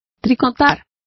Also find out how tricota is pronounced correctly.